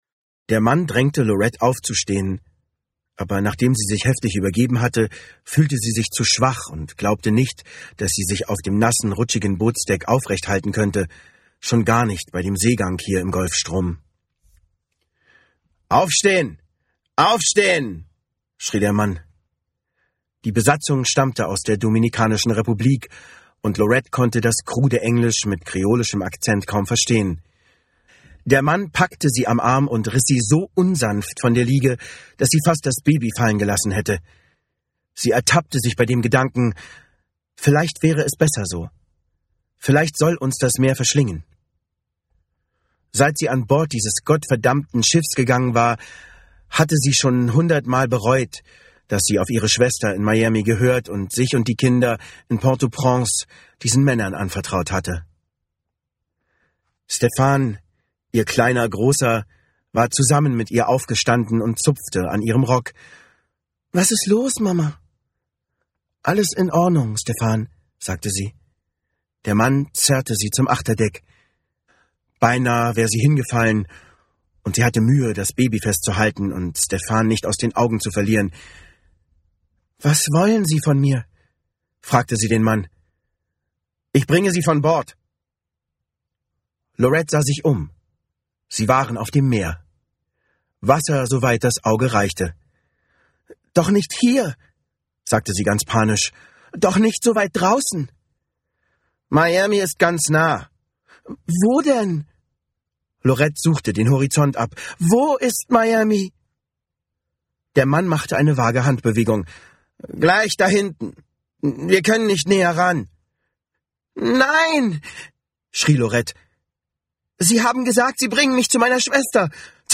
Imagefilm Conseta